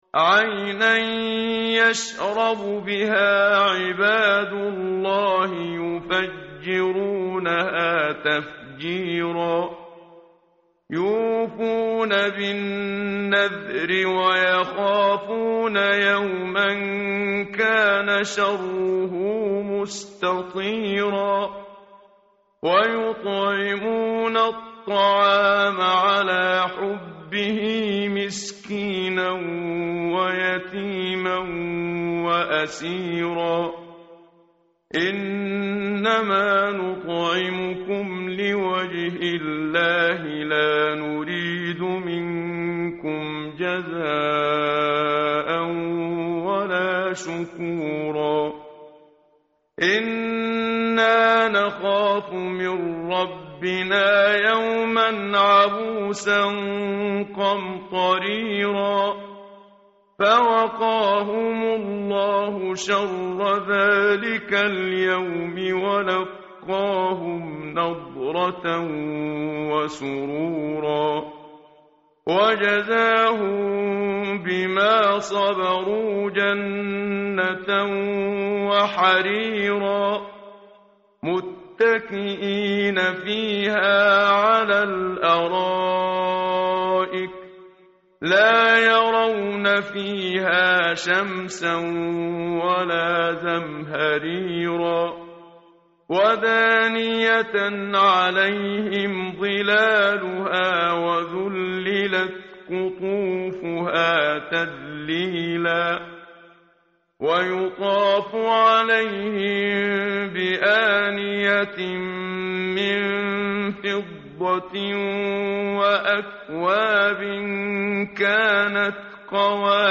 متن قرآن همراه باتلاوت قرآن و ترجمه
tartil_menshavi_page_579.mp3